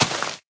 grass4.ogg